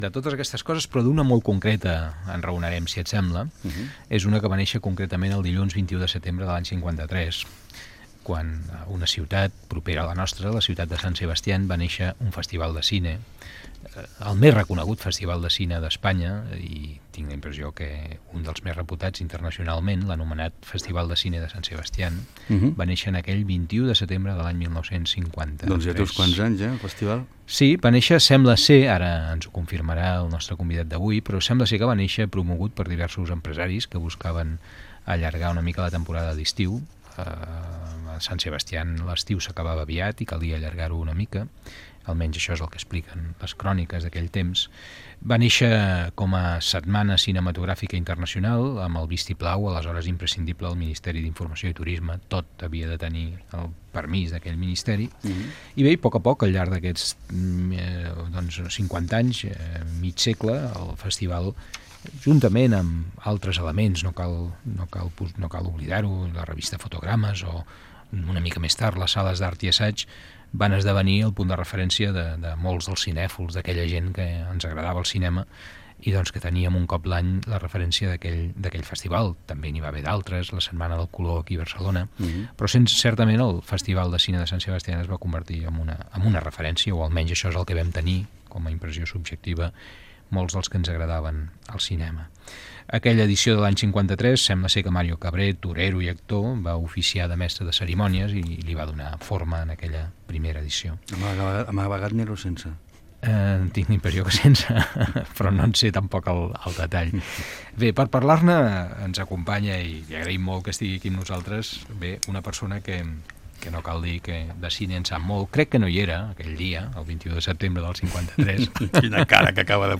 Recorden el 21 de setembre de 1953, primera edició del Festival de cinema de Sant Sebastià, amb el crític Jaume Figueras.
Fragment extret de l'arxiu sonor de COM Ràdio.